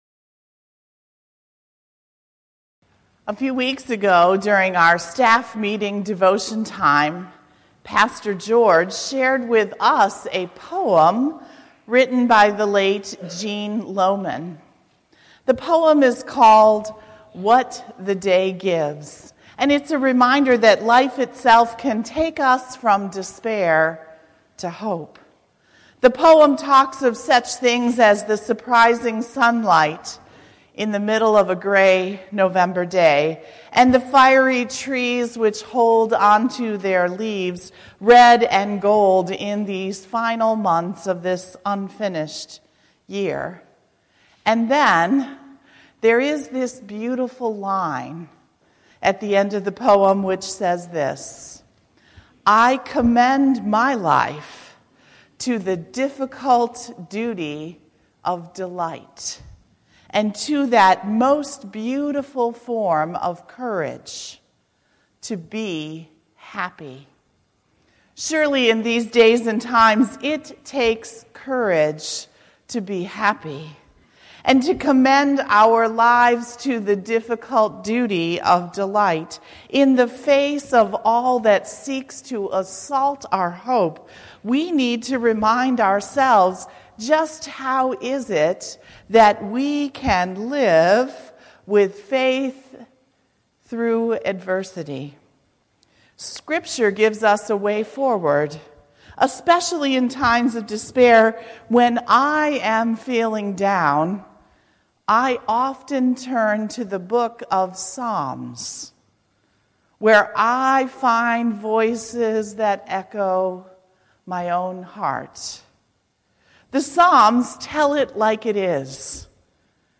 November 4, 2018 Sermon, “Showing Up With Hope”